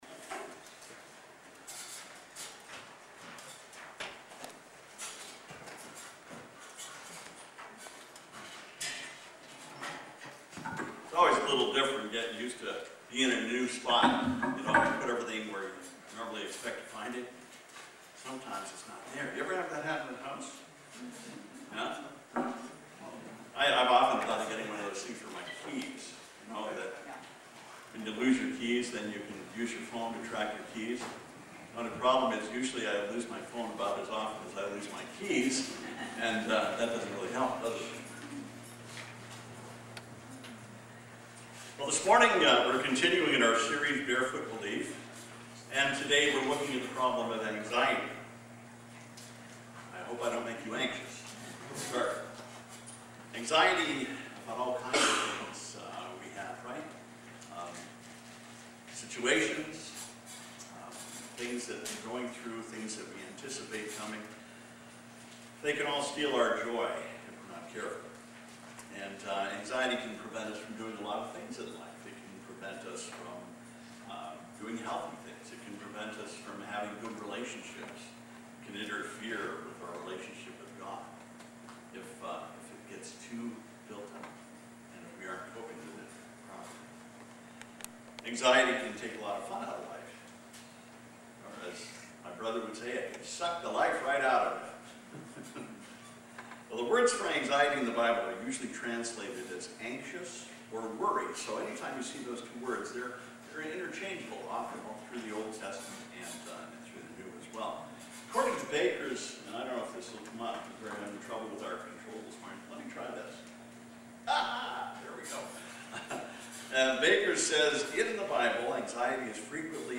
Barefoot Belief: “Anxiety” Philippians 4:6-7, Mathew 6:25-34 « FABIC Sermons